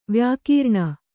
♪ vyākīrṇa